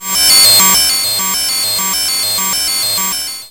边疆2》机器人语音 " 初级武器充电 01
标签： 充电 - 初级武器 机器人的语音 语音 Borderlands2 机器人 处理 装载机 游戏人声 机器人 变形的 变形 科幻 Borderl ANDS-2 游戏声音
声道立体声